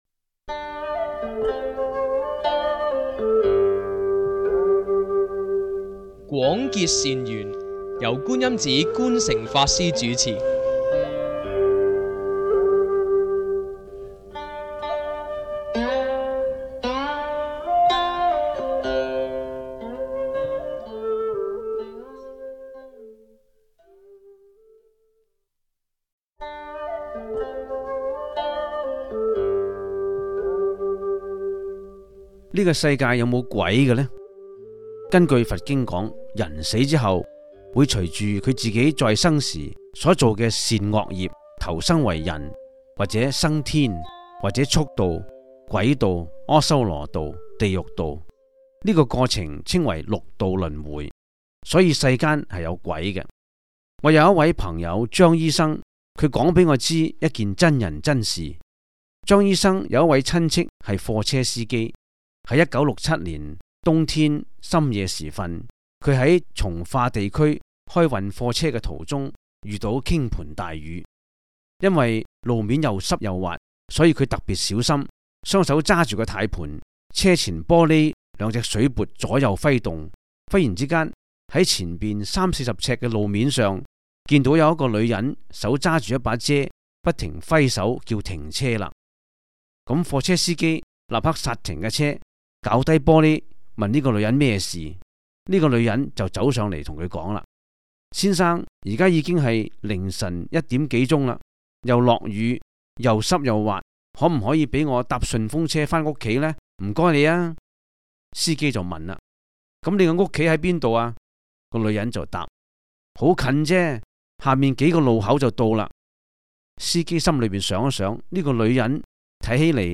第 三 十 一 辑    (粤语主讲 MP3 格式)